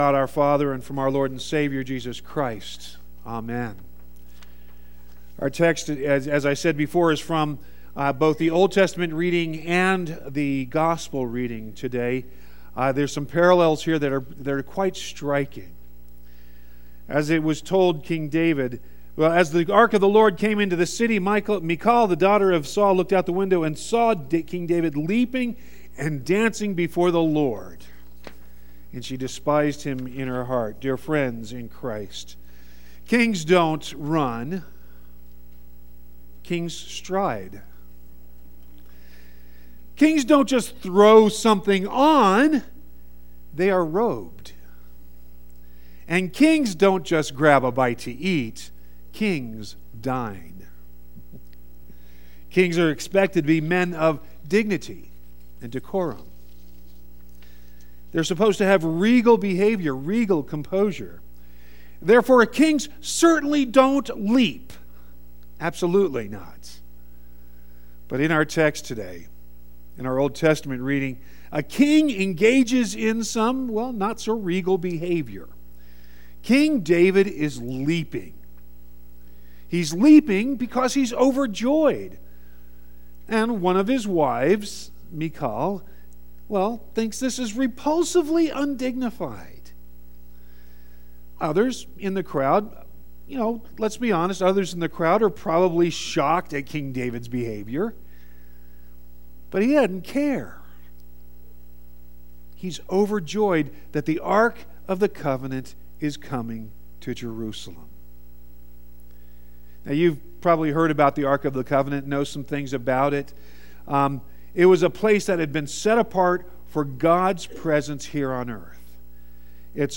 The sermon from the 19th was recovered, please press play below to hear the pastors sermon from that day.